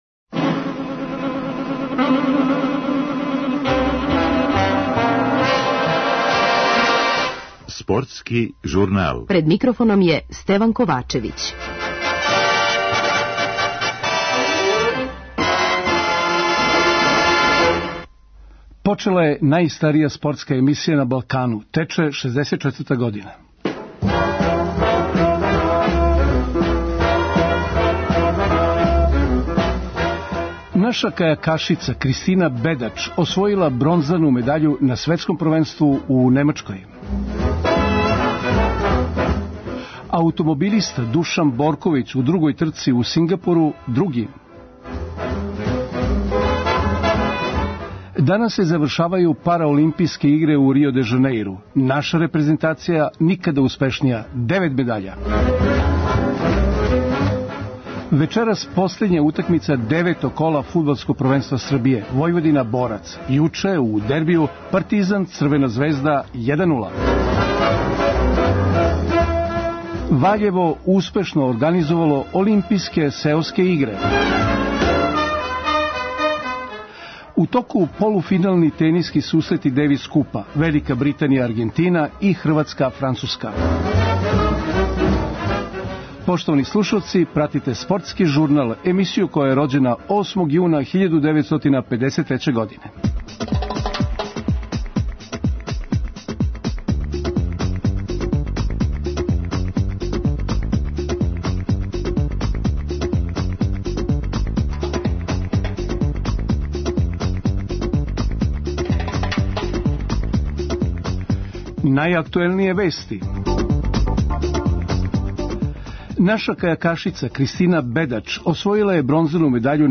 Осврт, уз изјаве актера утакмице